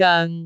speech
cantonese
syllable
pronunciation
goeng6.wav